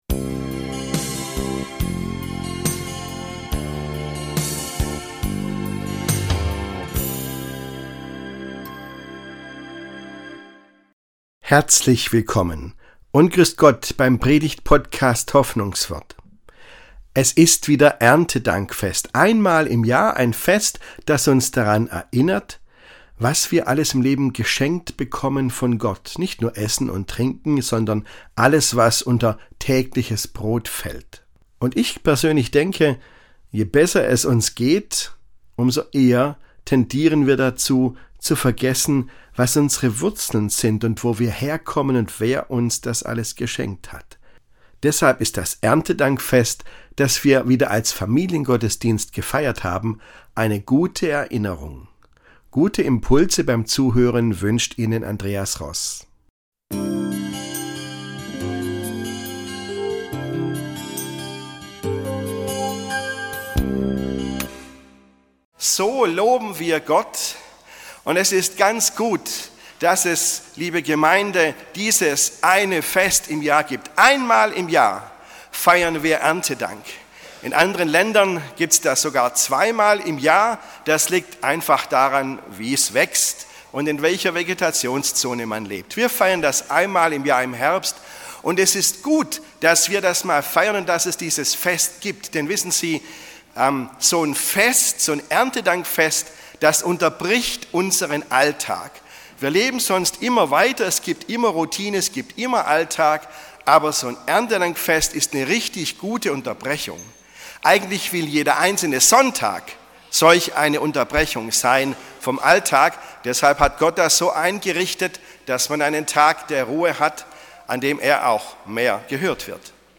Hoffnungswort - Predigten